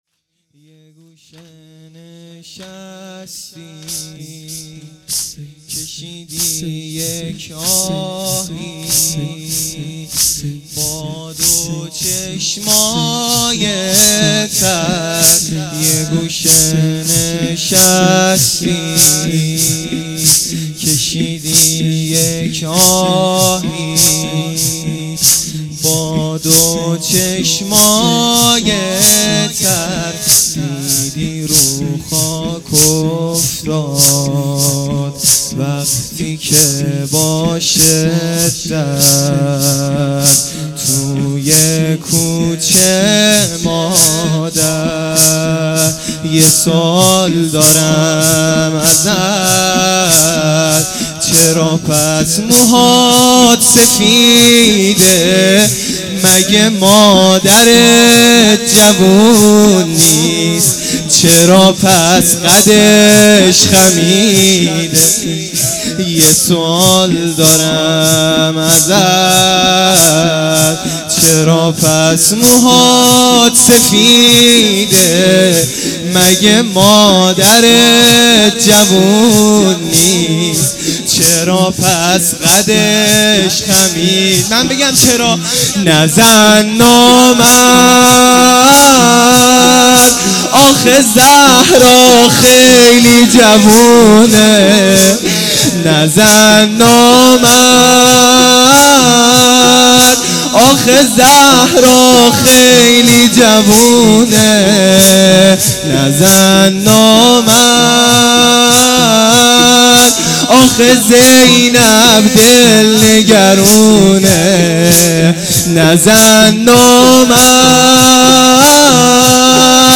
زمینه | نزن نامرد